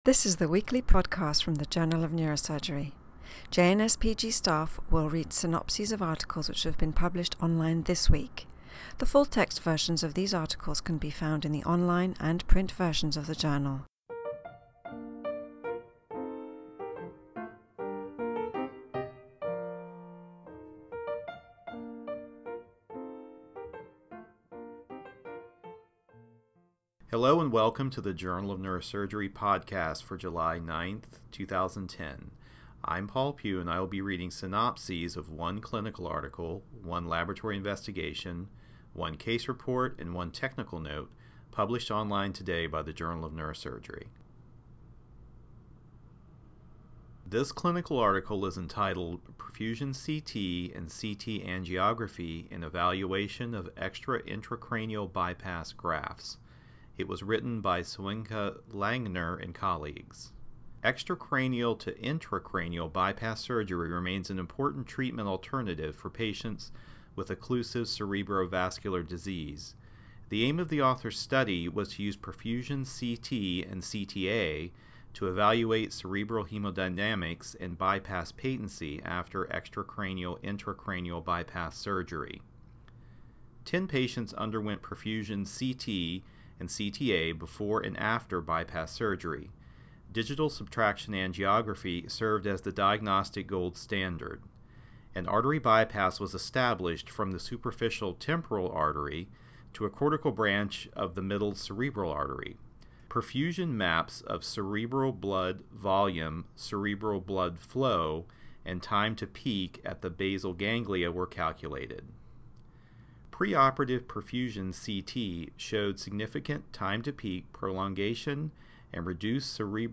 reads synopses of Journal of Neurosurgery articles published online on July 9, 2010.